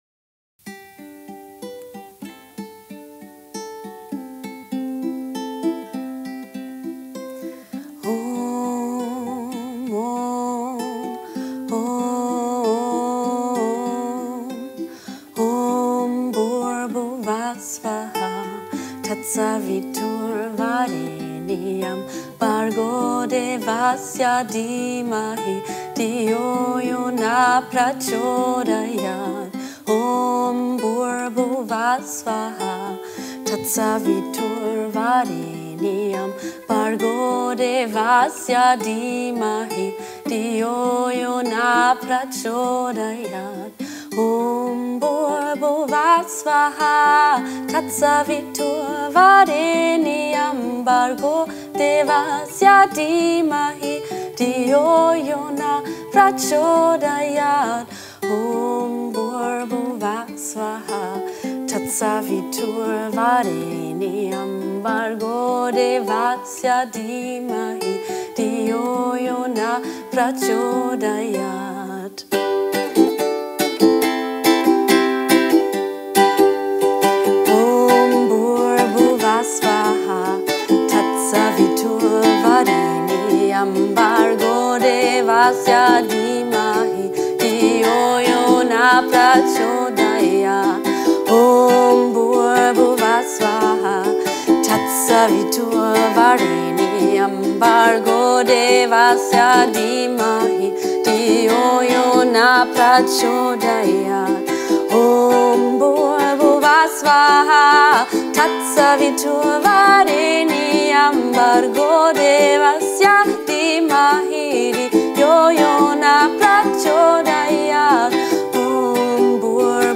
Das Gayatri Mantra ist eines der populärsten Mantras. Es ist die Anrufung des Göttlichen als Lichtenergie.